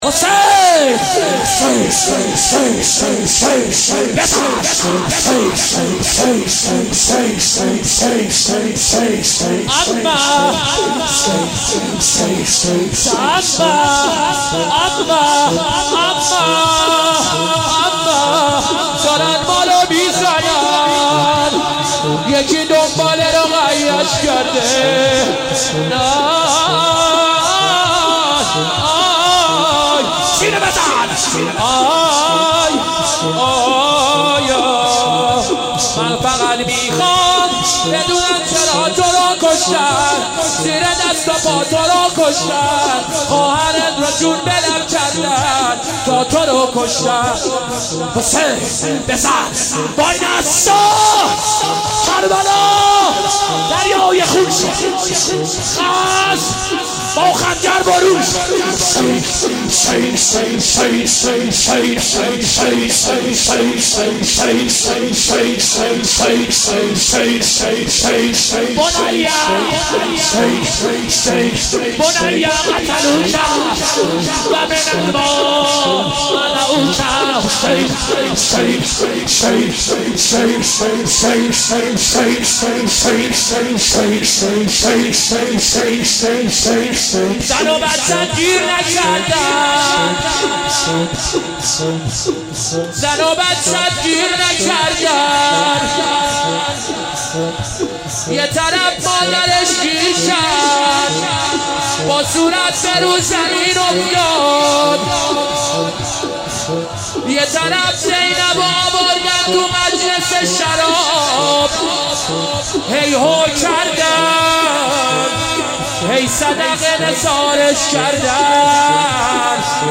شعرخوانی و روضه